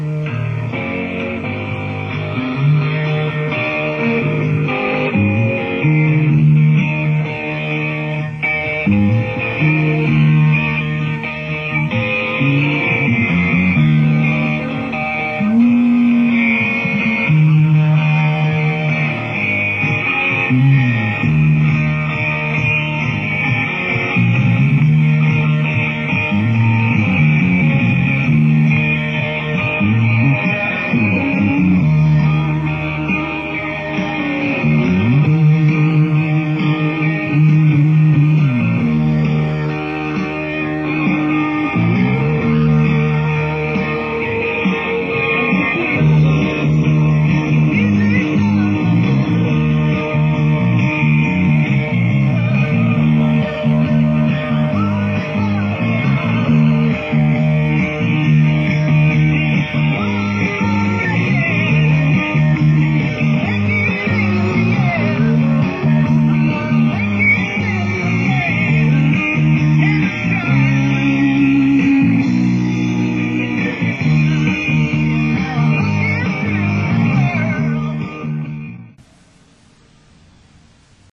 Schaefer Music Festival, Central Park